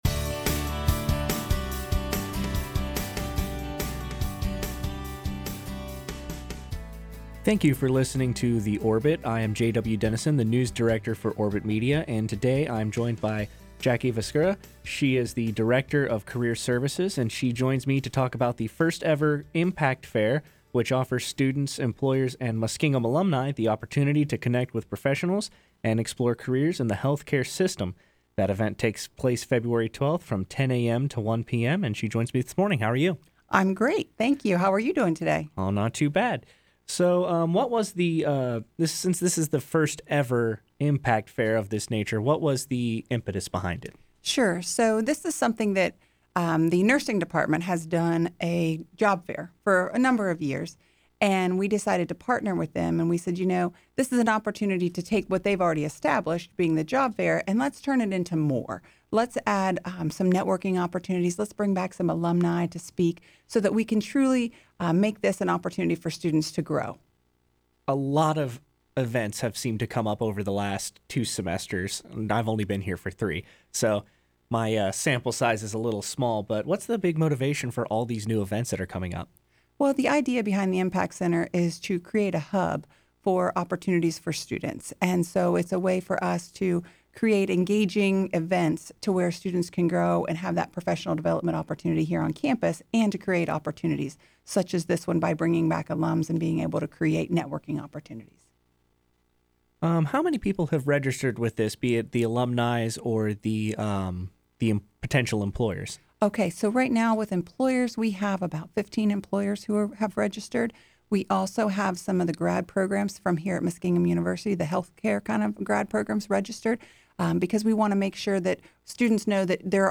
Impact_Fair_Interview_Mixdown_mixdown.mp3